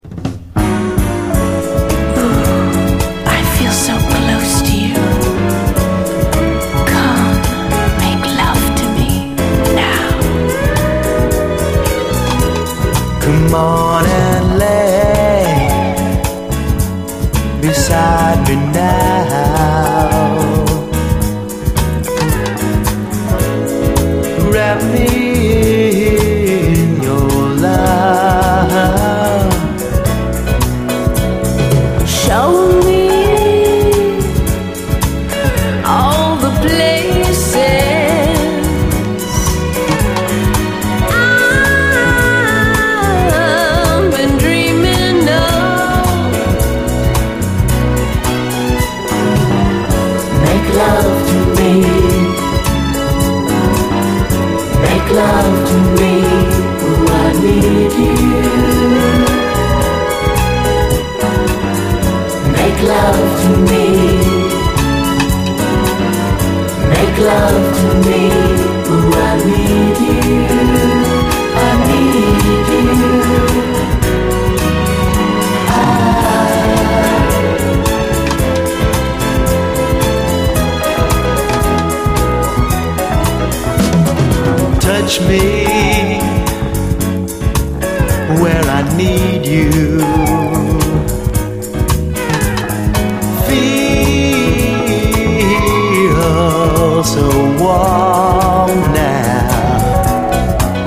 ブラック・サントラ〜レアグルーヴ名盤
レアグルーヴ・クラシックなスリリングな同インスト・ヴァージョン
華麗に駆け抜けるジャジー・トラック
スウィート・ソウル